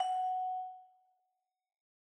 vibraphone.ogg